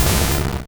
Cri de Sablaireau dans Pokémon Rouge et Bleu.